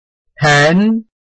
臺灣客語拼音學習網-客語聽讀拼-饒平腔-鼻尾韻
拼音查詢：【饒平腔】pen ~請點選不同聲調拼音聽聽看!(例字漢字部分屬參考性質)